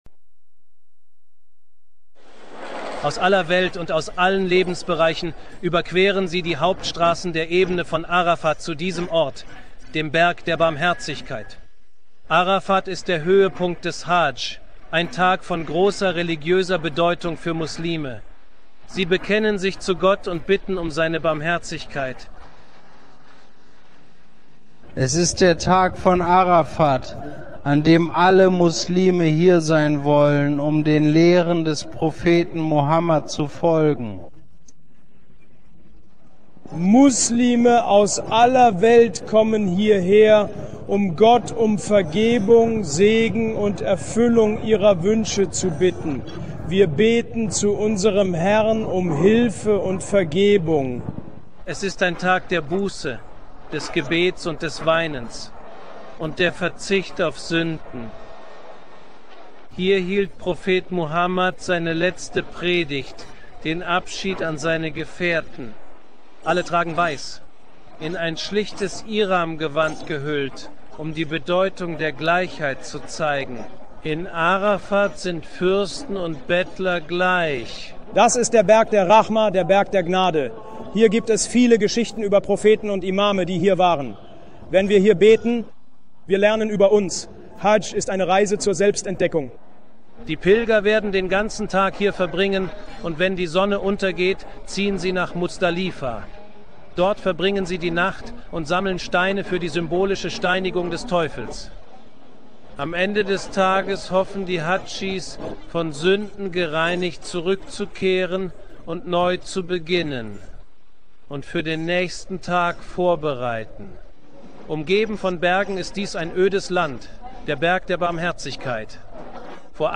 Beschreibung: Dieses Video ist ein Nachrichtenbeitrag von Al-Jazeerah über die Tugenden der Hadsch.